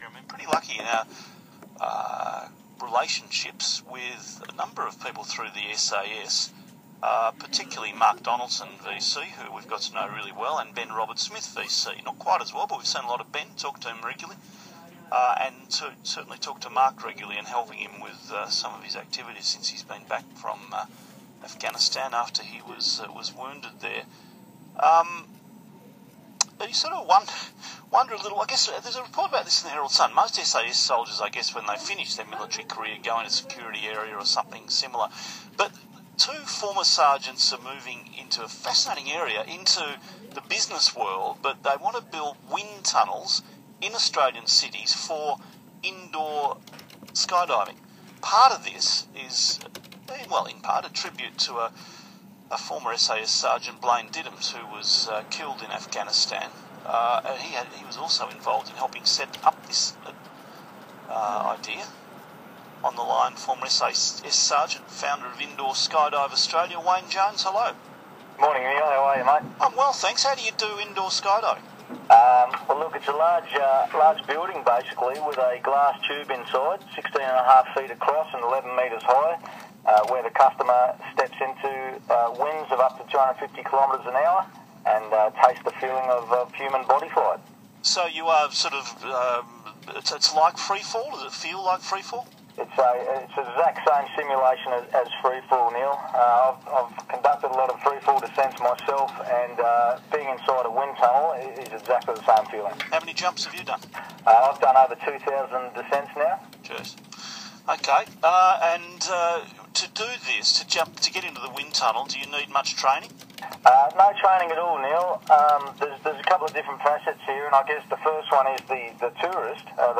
ISAG Neil Mitchell interview 26 Nov – 2GB
ISAG-Neil-Mitchell-interview-26-Nov-2GB.m4a